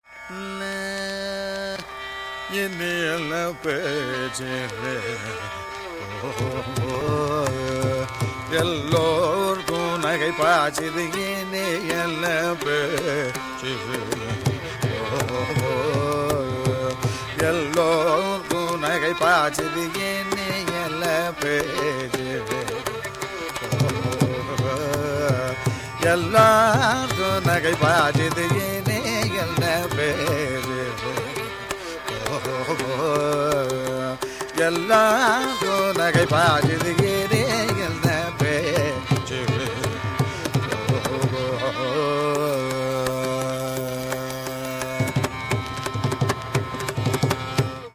Caturaśra Rūpaka Tāḷa: O |4
Rāga Śahāna
pallavi